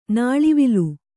♪ nāḷivilu